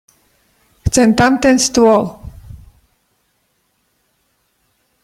pronunciation_sk_chcem_tamten_stol.mp3